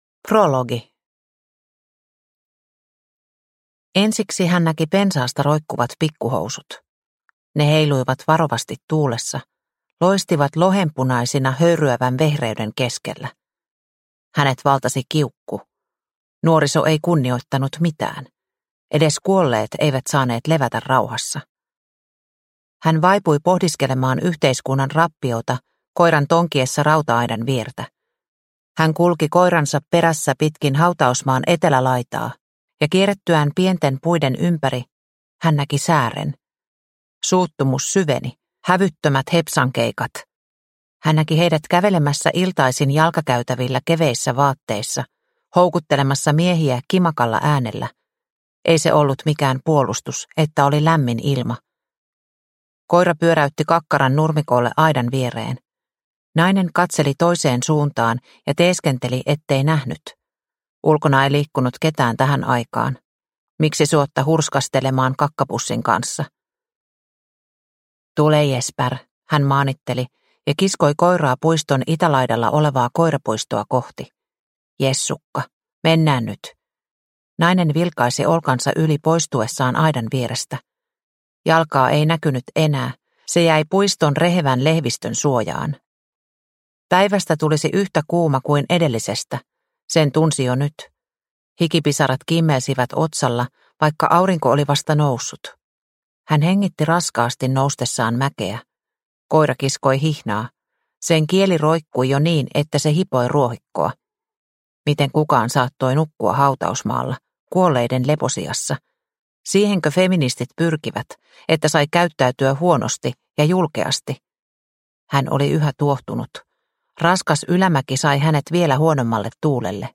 Studio sex – Ljudbok – Laddas ner